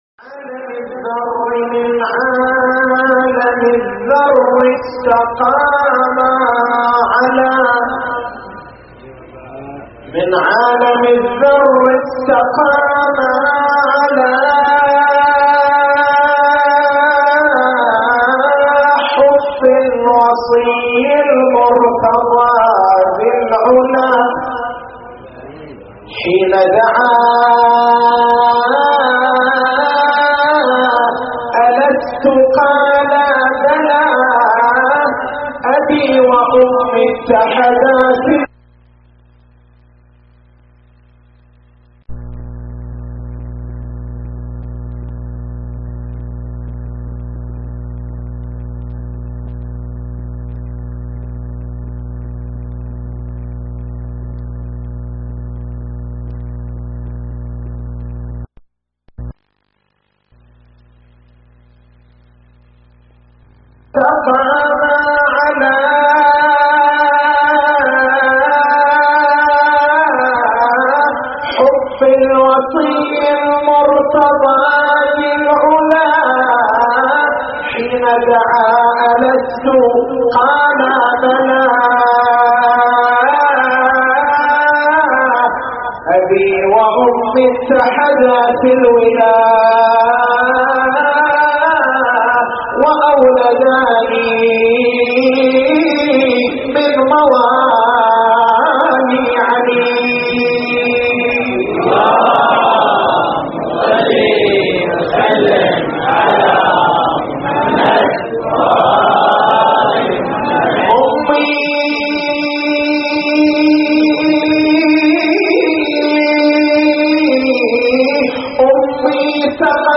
تاريخ المحاضرة: 13/07/1425 محور البحث: هل عقيدة الشيعة – التي عبّر عنها شعراؤهم – في حب أمير المؤمنين (ع) توجب الإغراء بالمعصية؟